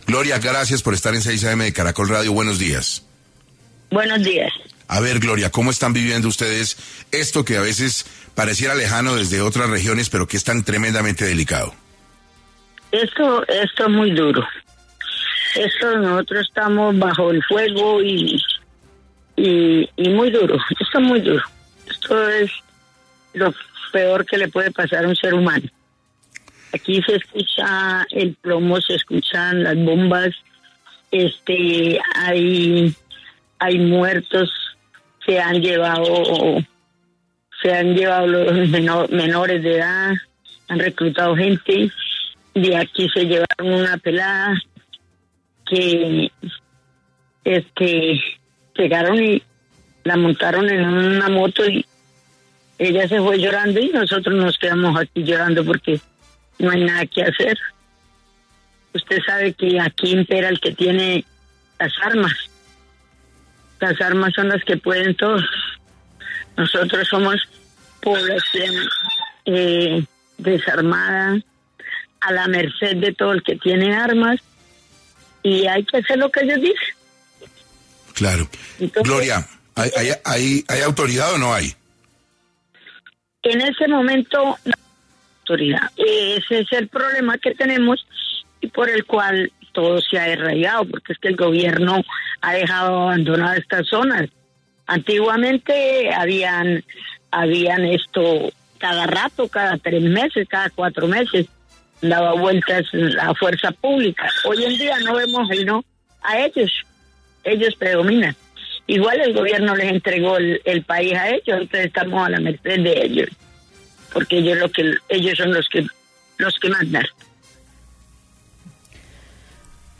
Mujer confinada en el Catatumbo narra la violencia que se vive en la región por los intensos enfrentamientos entre el Ejército de Liberación Nacional (ELN) y una disidencia de las extintas FARC.